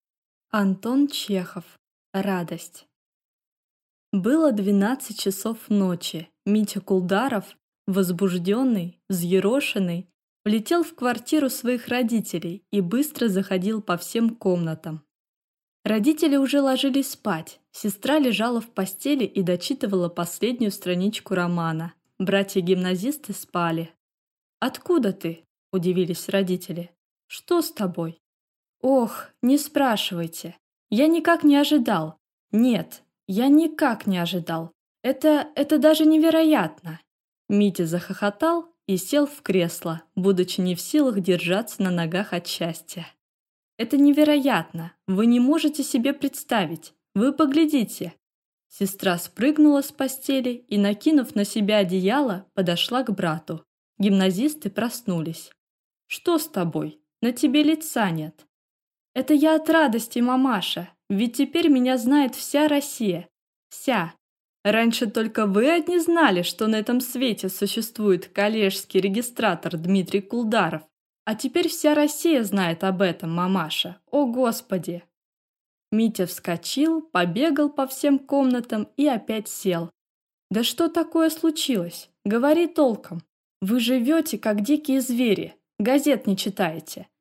Аудиокнига Радость | Библиотека аудиокниг